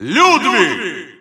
Announcer pronouncing Ludwig in Spanish.
Ludwig_Russian_Announcer_SSBU.wav